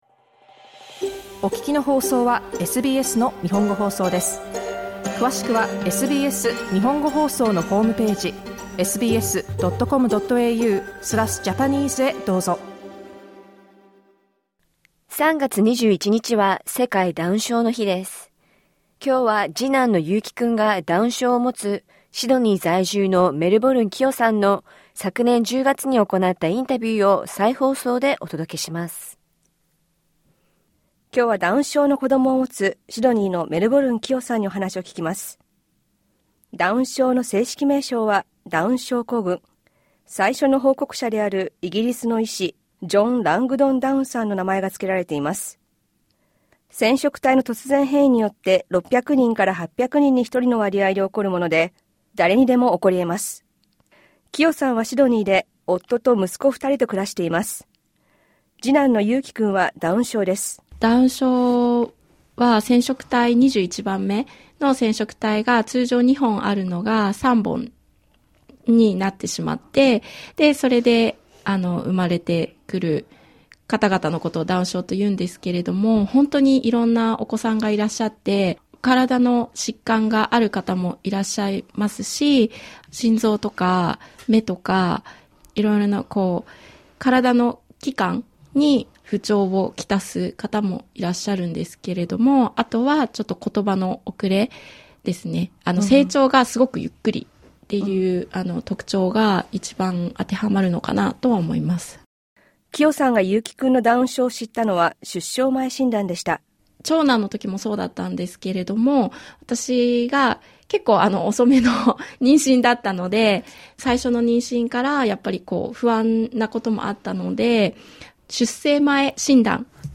インタビューでは、診断を受けた後の気持ちや夫との話し合い、２人育児などについて聞きました。